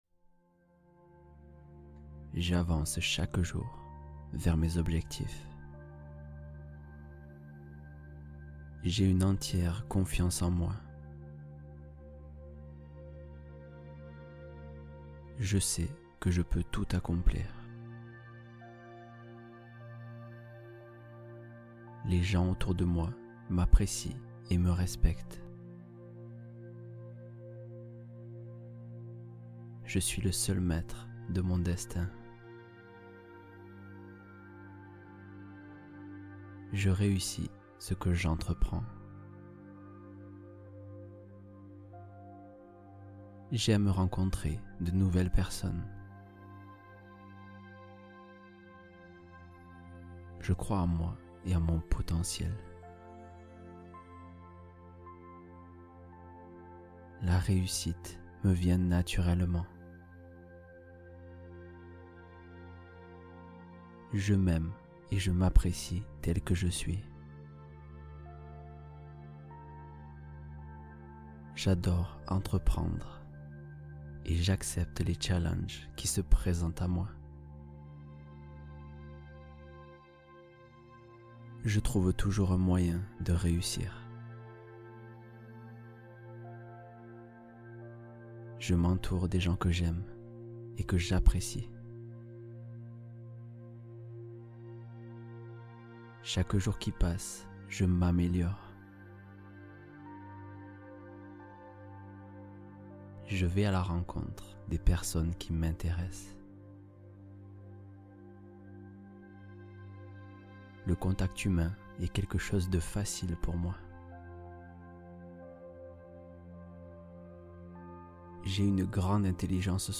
Méditation du soir : plonge dans un sommeil profond